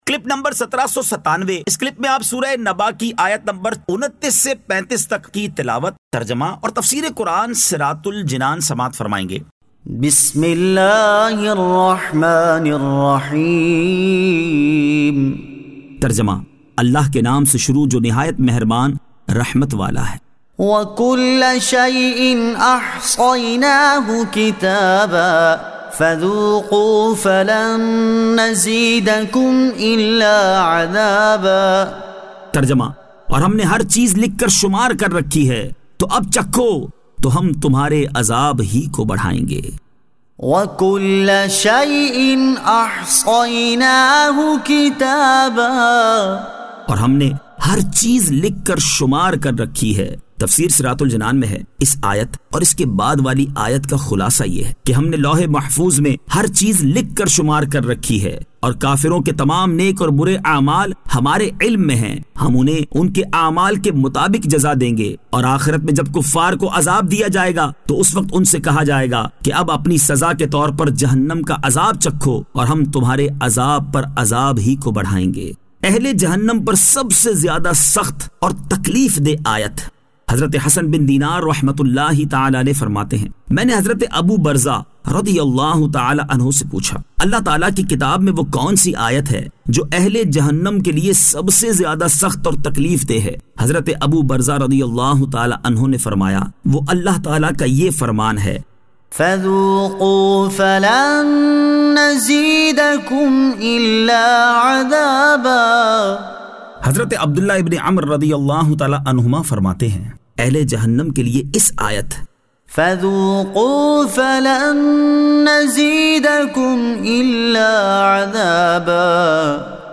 Surah An-Naba 29 To 35 Tilawat , Tarjama , Tafseer